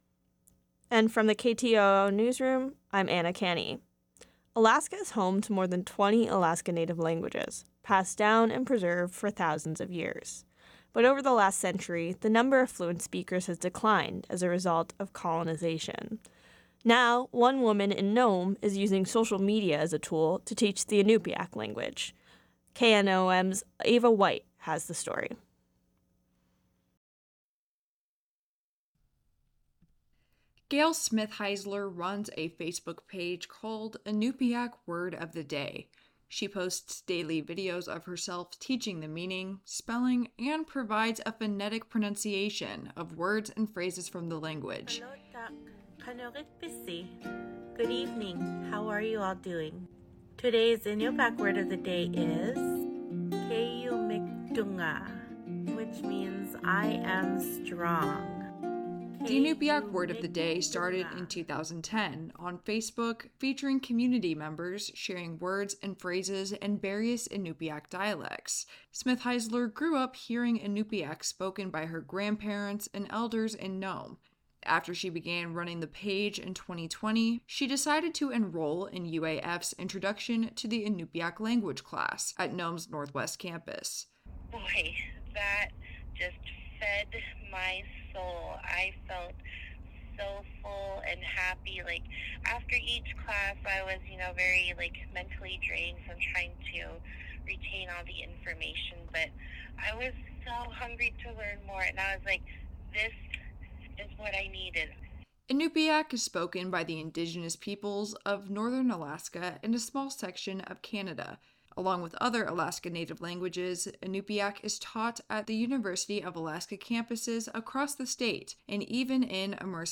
Newscast – Friday, Oct. 6, 2023